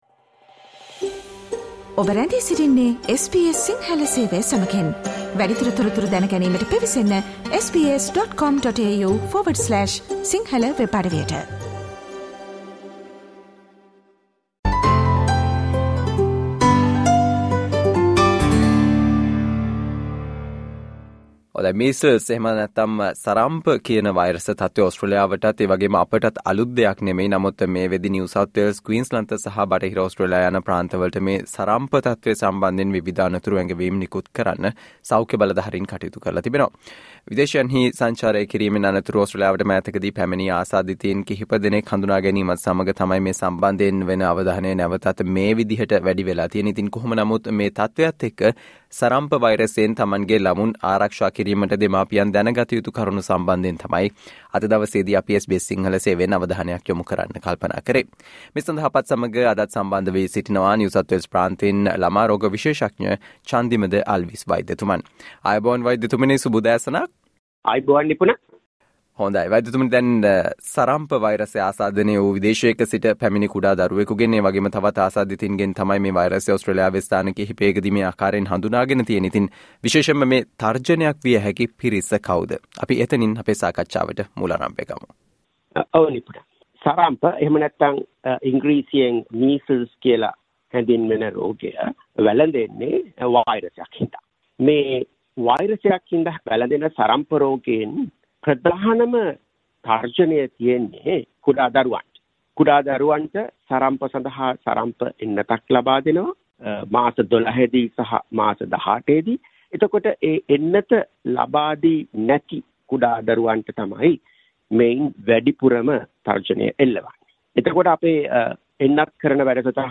Listen to the SBS Sinhala discussion on What parents should do to protect their child from the "Measles", which has already been issued warnings